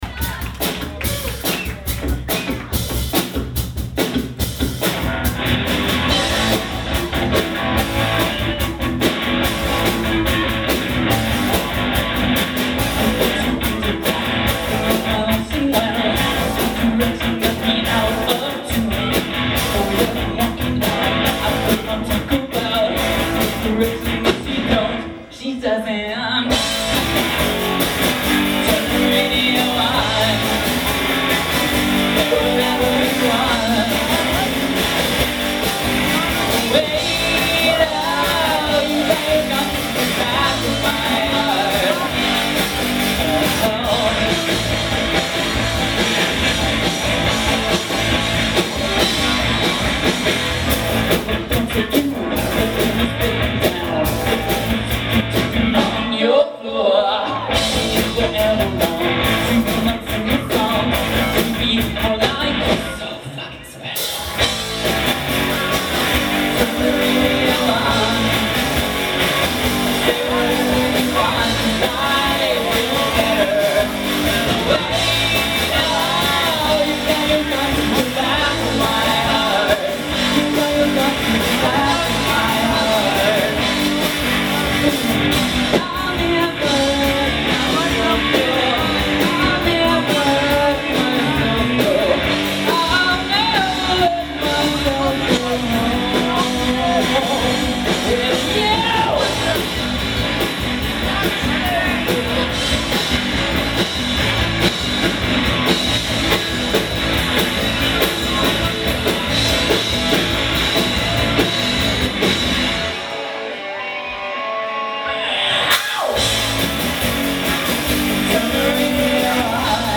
Genre: Metal